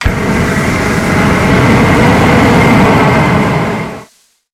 flamethrower_shot_02.wav